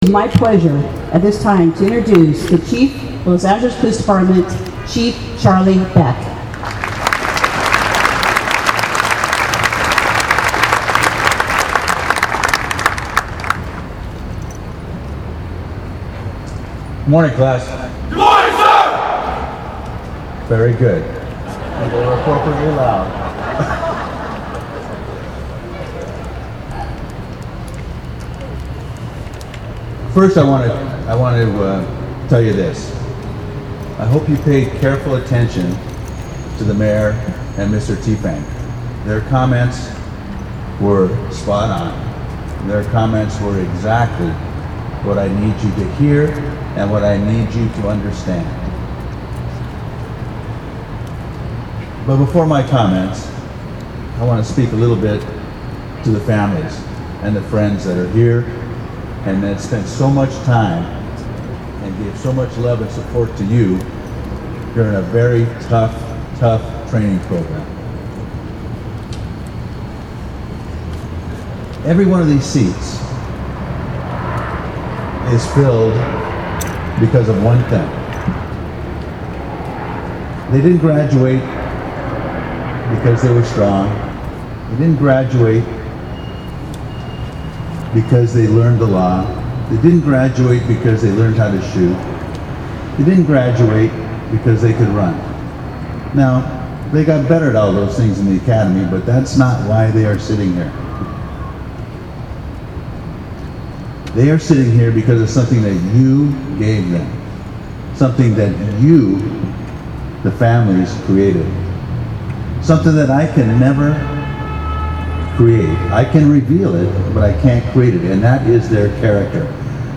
On July 10, 2015, a Los Angeles Police Academy graduation ceremony was held at the LAPD Administration Building plaza. The ceremony featured 39 graduates, five of which were for Los Angeles World Airports.
cop-grad-speech-7-10-15.mp3